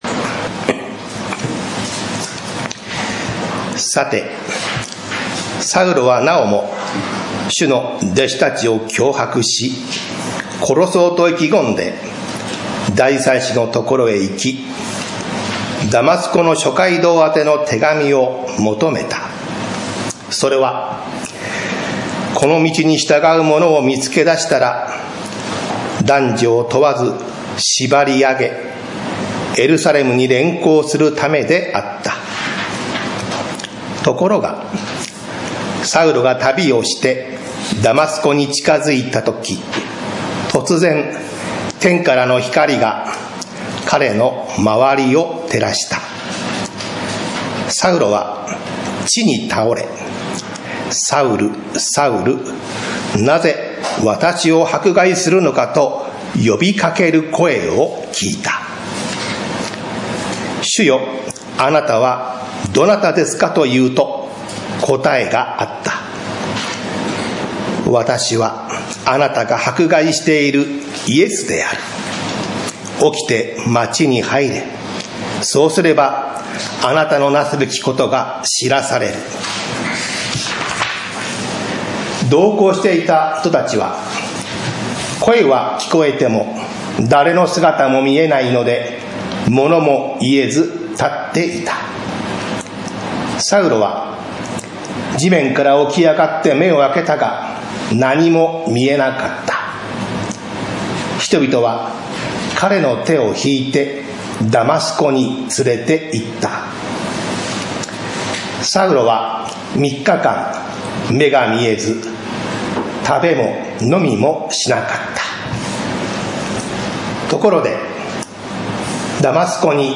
栃木県鹿沼市 宇都宮教会
日曜 朝の礼拝